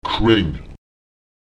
Lautsprecher ken [k¨EN] die Provinz